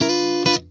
FUNKNCHUNK7.wav